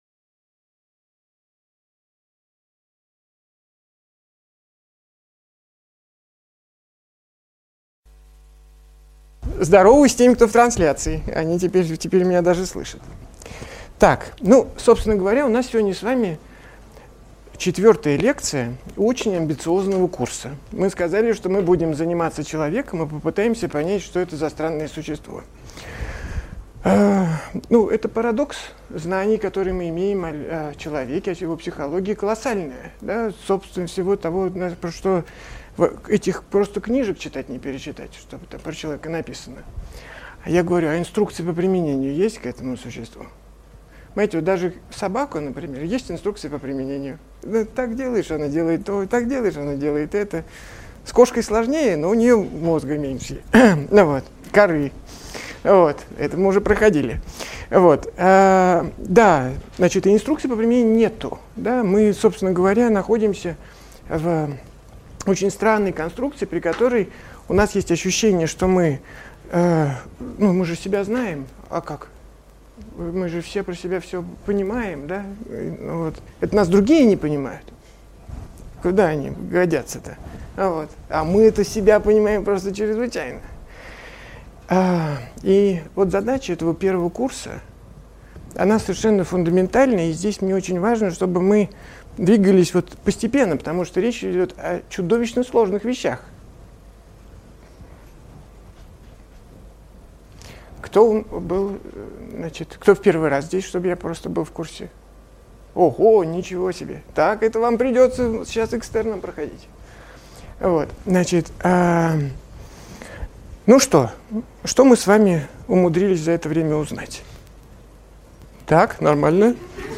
Аудиокнига Лекция №4 «Что я могу „знать“?»
Автор Андрей Курпатов Читает аудиокнигу Андрей Курпатов.